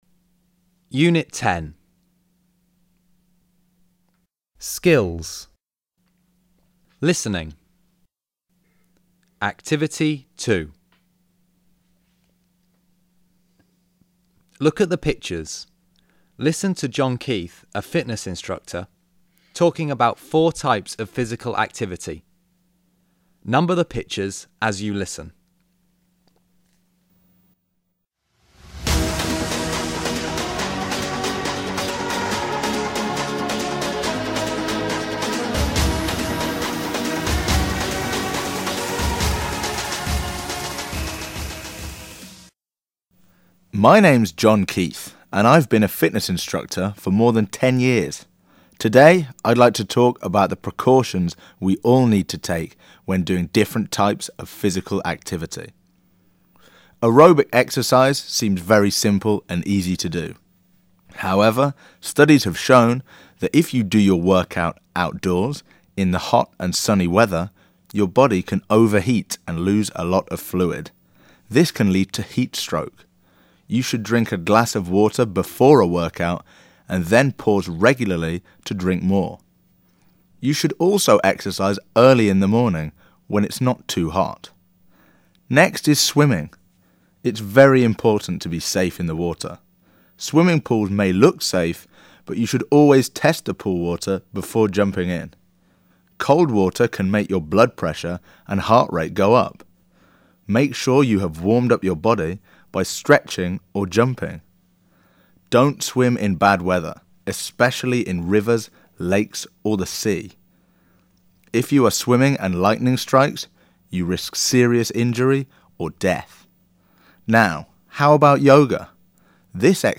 Listen to John Keith, a fitness instructor, talking about four types of physical activity.